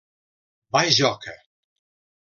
Pronunciado como (IPA) [bəˈʒɔ.kə]